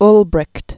(lbrĭkt, -brĭt), Walter 1893-1973.